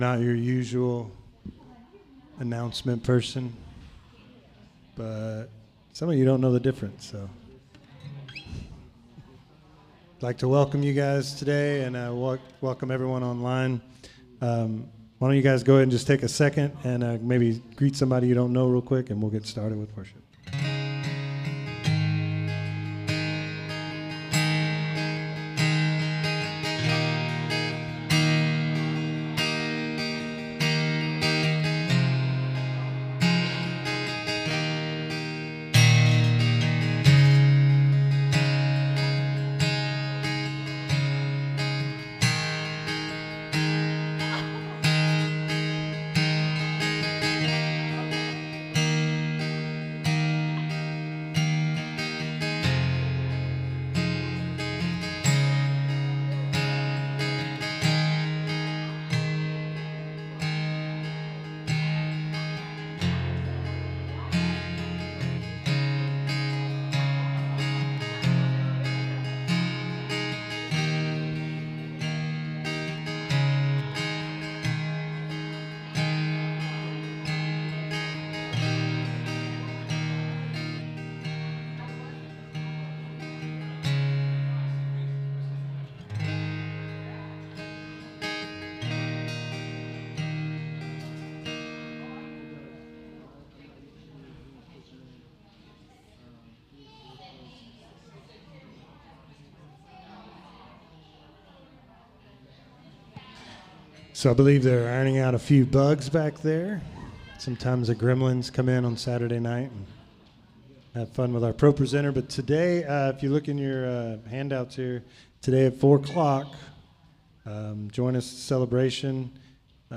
SERMON DESCRIPTION Family is an important part of our life.